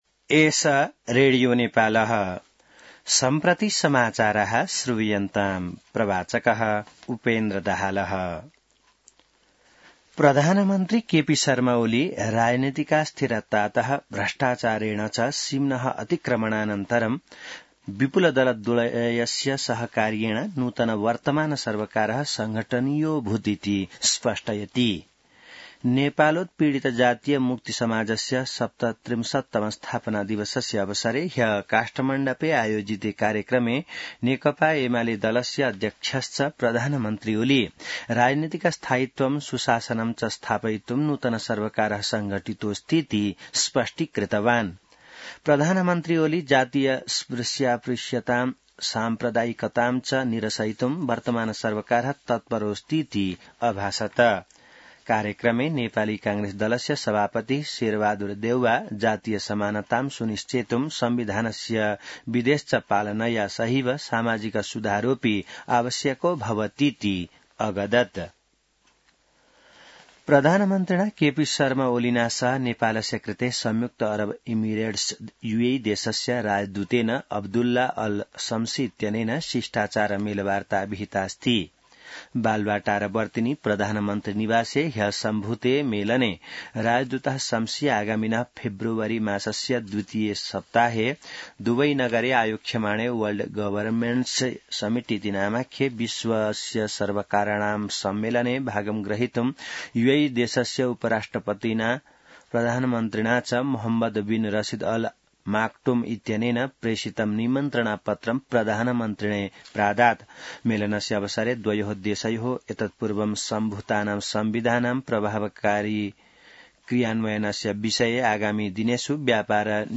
संस्कृत समाचार : ५ पुष , २०८१